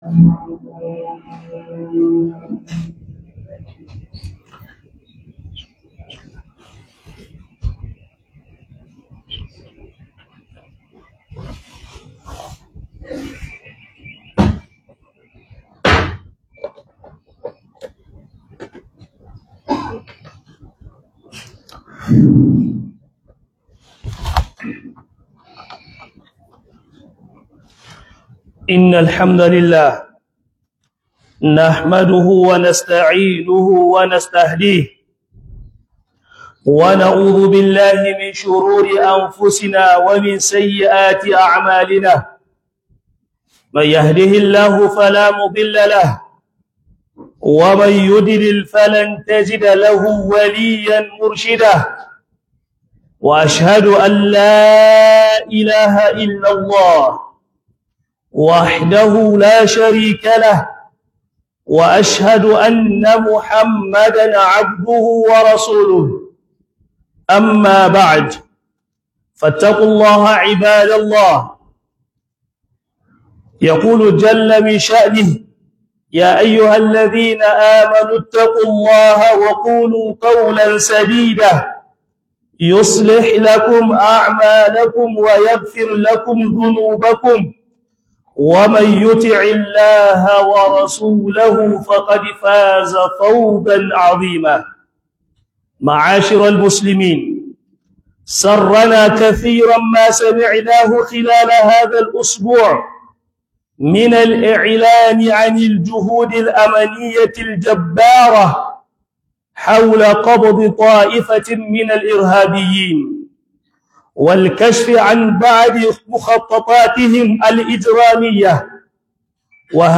Haɗarin Taimakama Inta'adda - HUDUBA